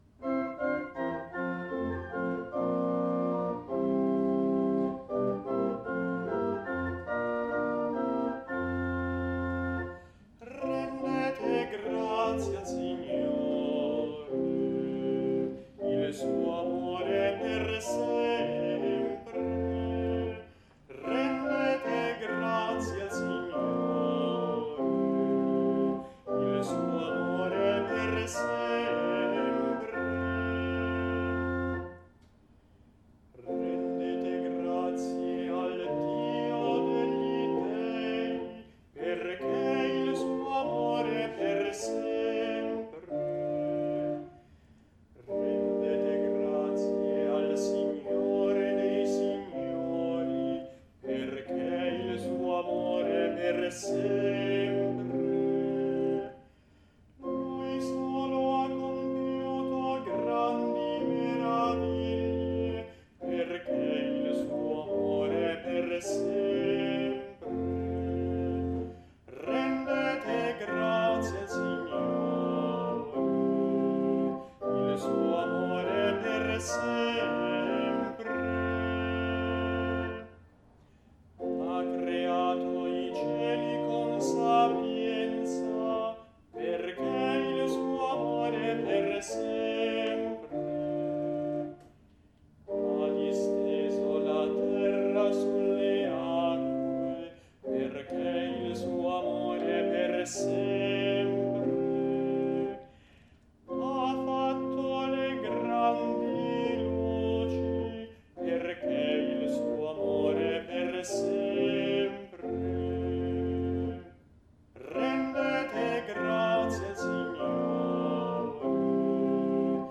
Audio esecuzione a cura degli animatori musicali del Duomo di Milano